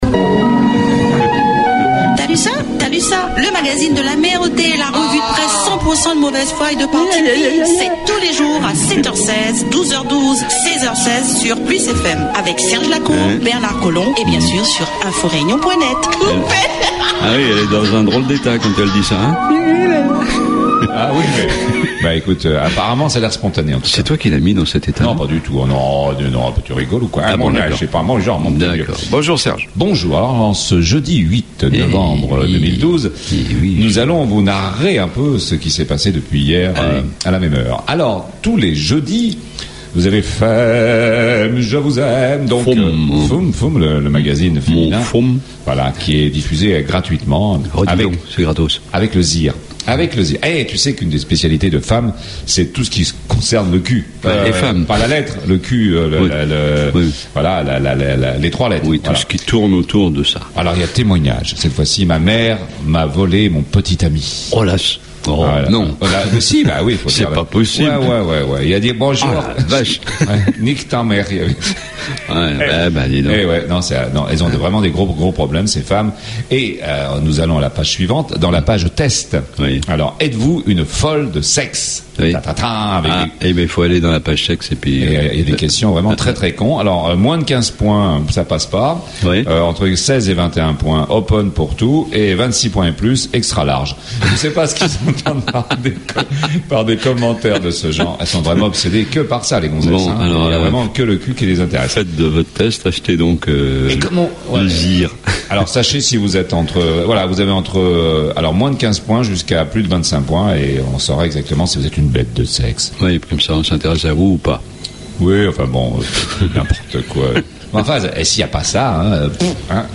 La Revue de Presse politiquement incorrecte la mieux informée, la plus décalée, la plus drôle, la moins sérieuse et surtout la plus écoutée sur PLUS FM 100.6 sur le Nord de l'ile et 90.4 dans l'ouest...